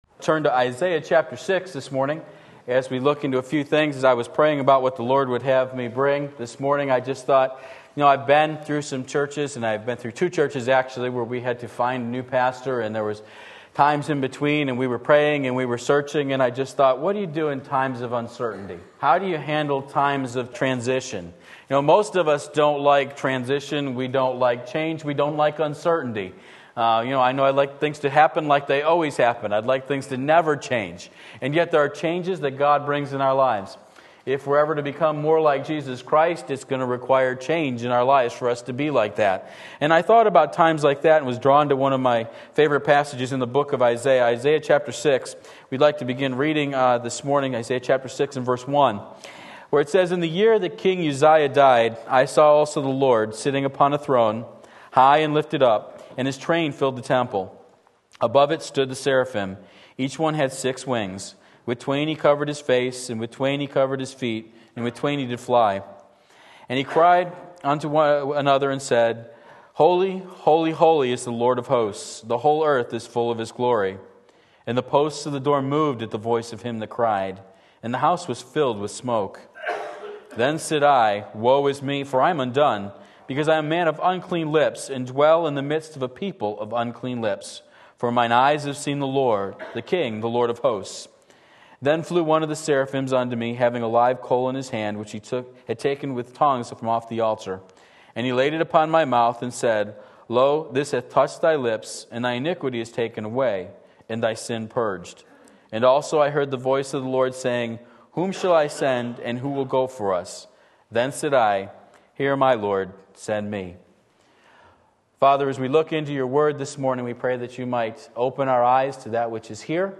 Sermon Link
In Times of Uncertainty Isaiah 6:1-8 Sunday Morning Service